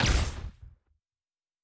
pgs/Assets/Audio/Sci-Fi Sounds/Weapons/Weapon 15 Shoot 3.wav at master
Weapon 15 Shoot 3.wav